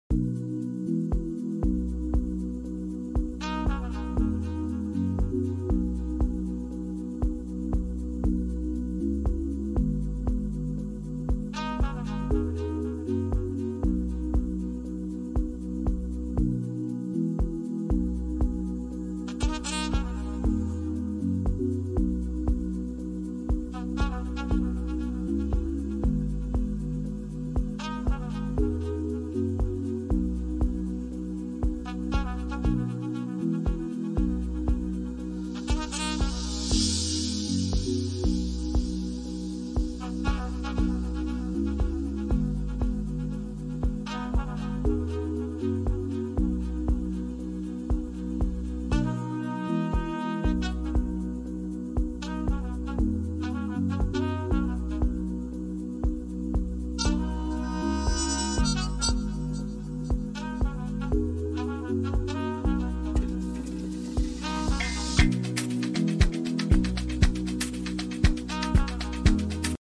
cuaght on camera in cape town CBD Signal hill